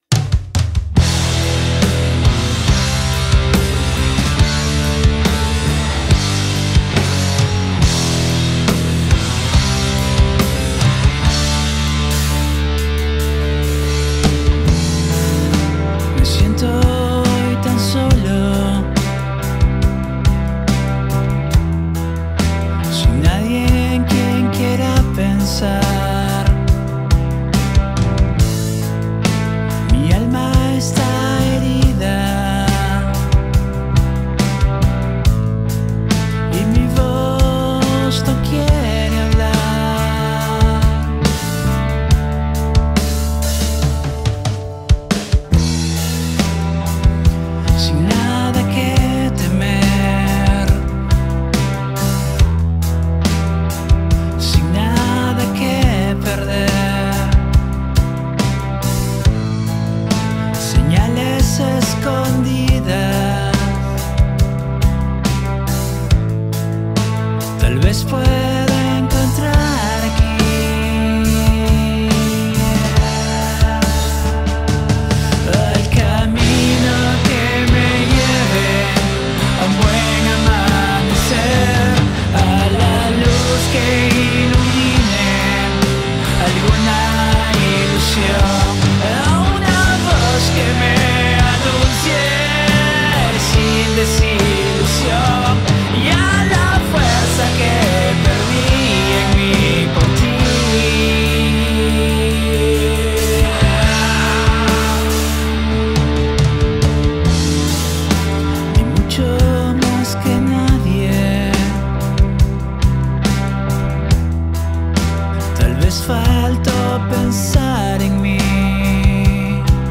Pop music Rock